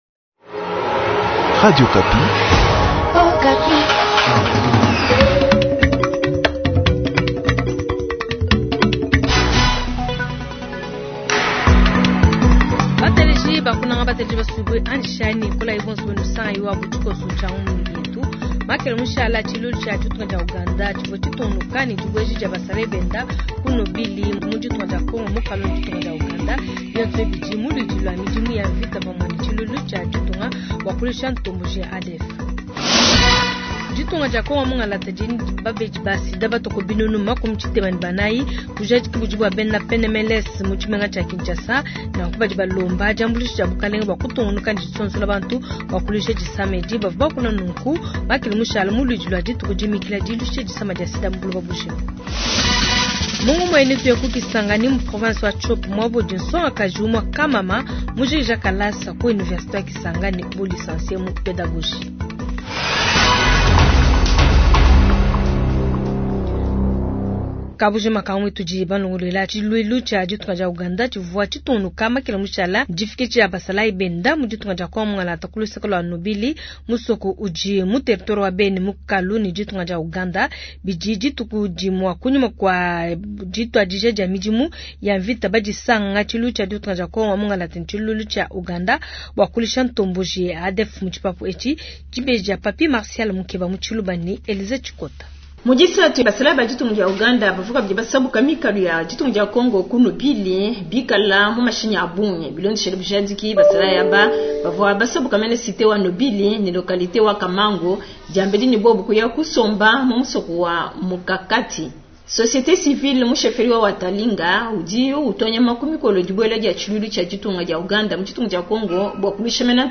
Journal du Jeudi 021221